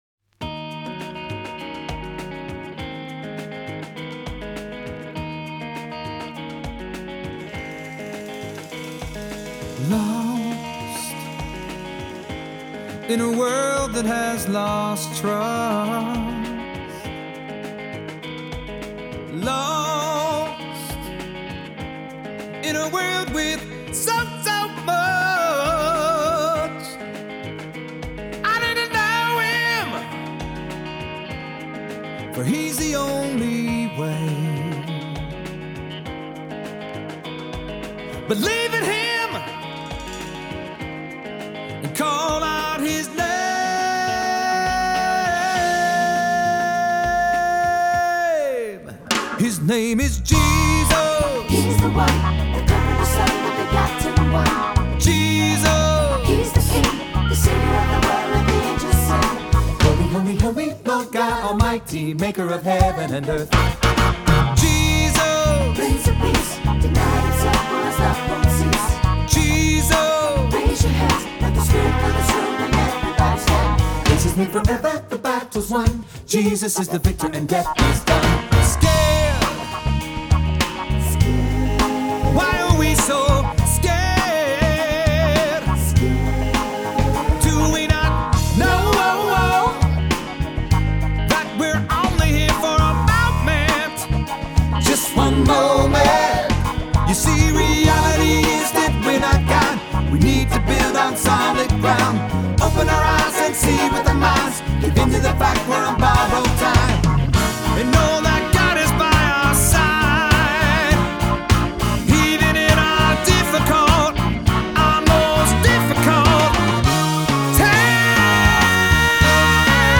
Voicing: Assembly,SATB,Soloist or Soloists,2-4-part Choir